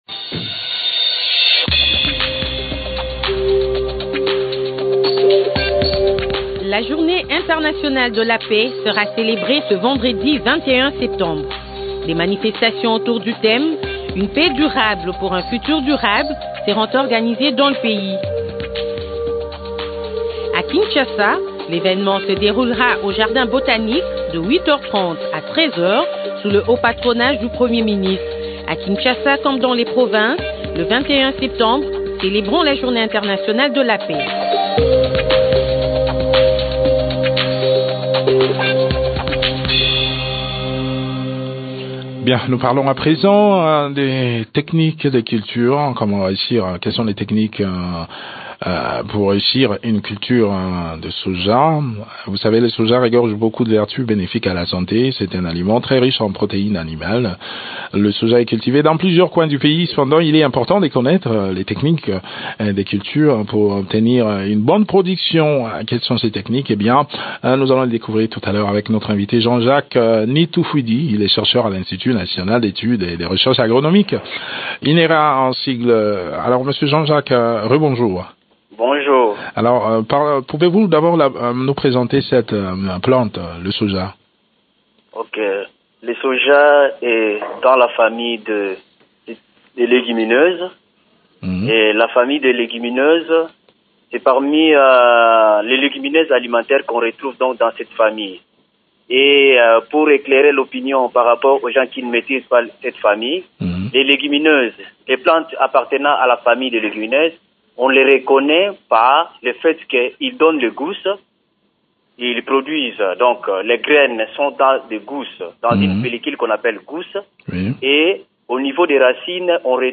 cet entretien